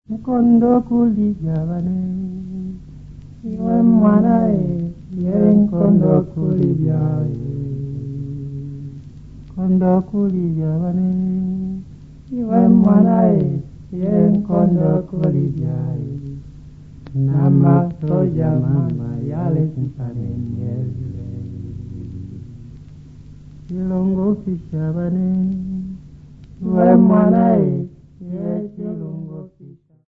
Two Bemba men singers
Memorial service
Vocal duets
Sub-Saharan African music
field recordings
Playing an unaccompanied duet performance recording in Bemba in memory of Hugh Tracey
Cassette tape